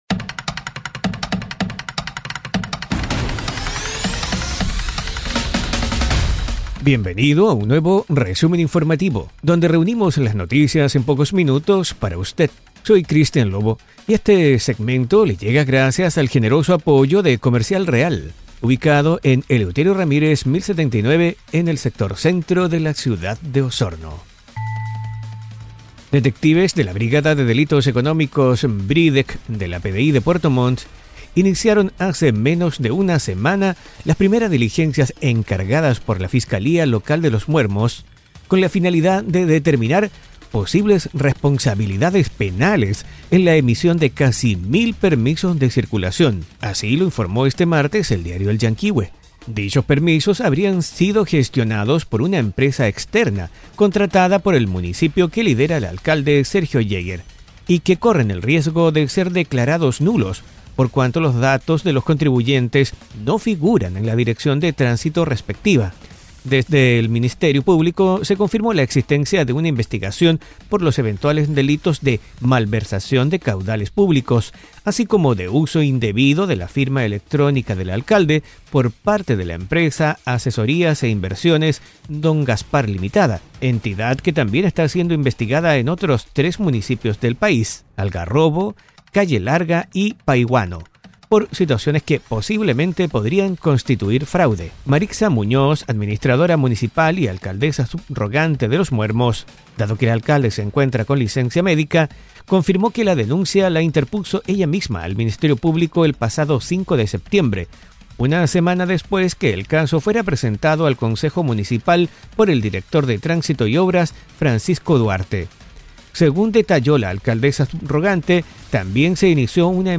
En este podcast, te presentamos un resumen ágil y detallado de las noticias más relevantes de la Región de Los Lagos.